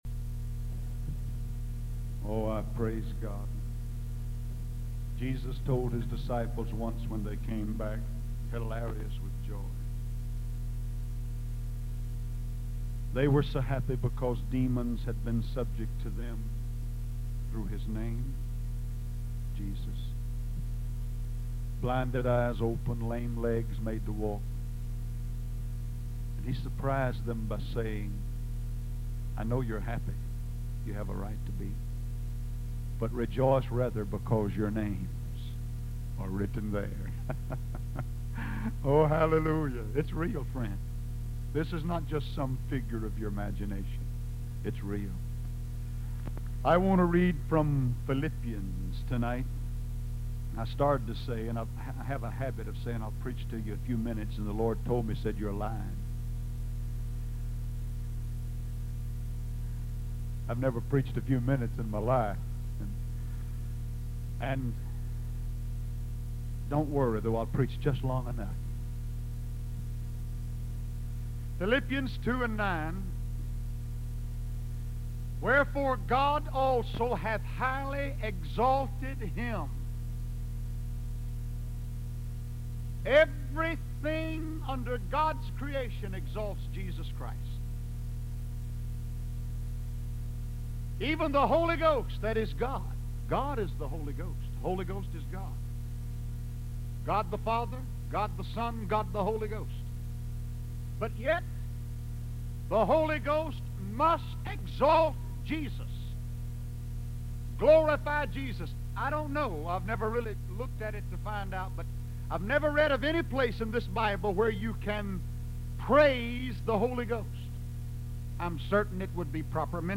The Name of Jesus (Some Bad Audio)
Jmmy+Swaggart+-+The+Name+of+Jesus+-Bad+Audio.mp3